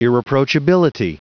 Prononciation du mot irreproachability en anglais (fichier audio)